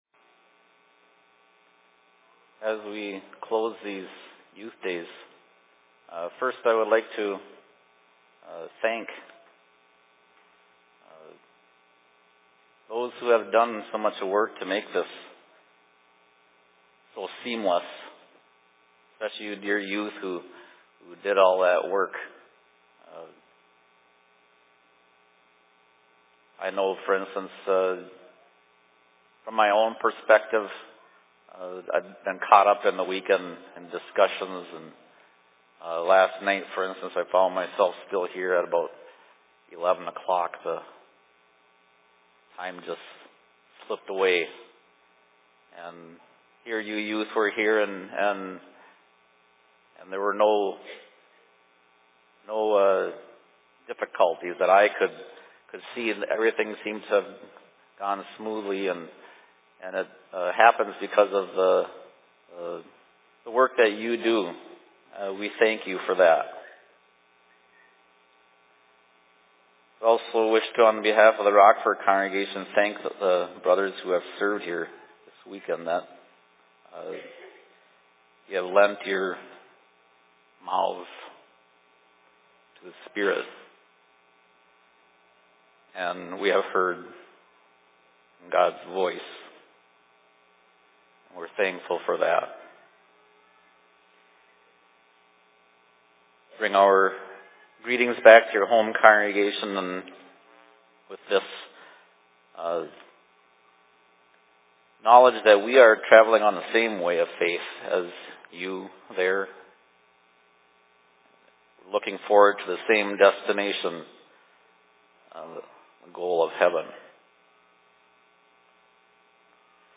Youth Days/Sermon in Rockford 11.08.2019
Location: LLC Rockford